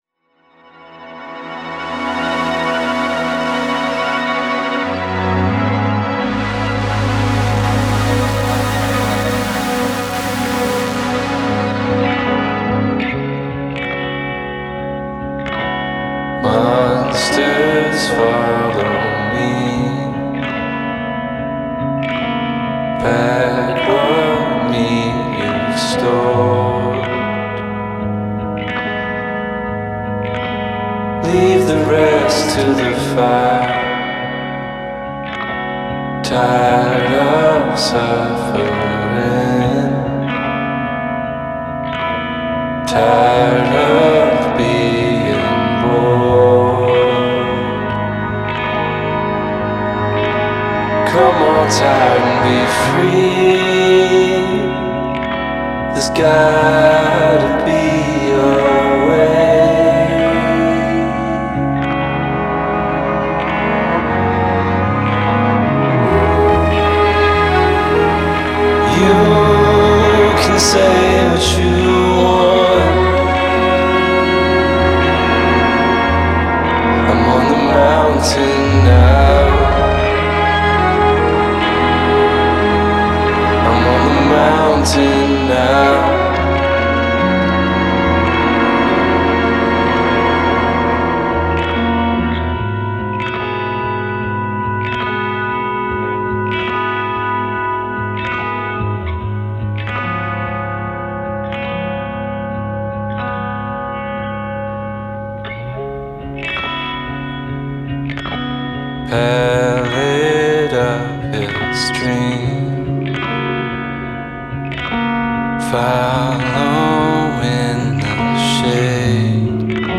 The string arrangement